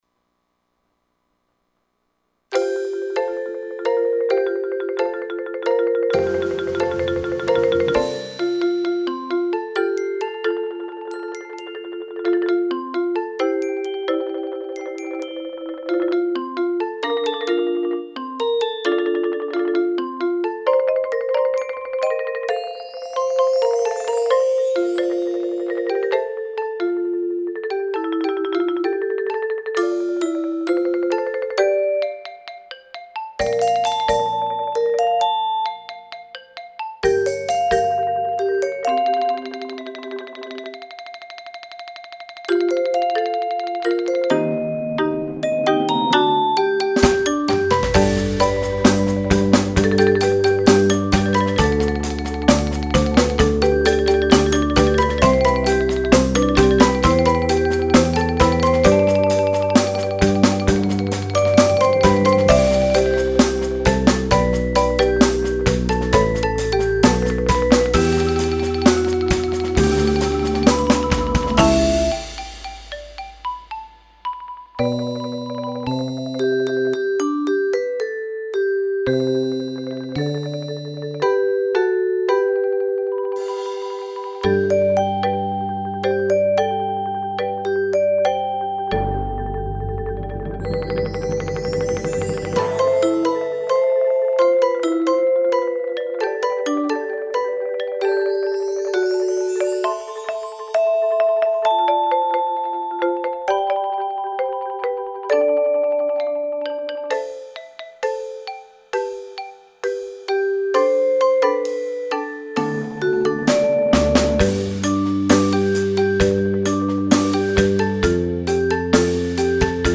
Bladmuziek voor junior percussie ensemble, gestemd slagwerk.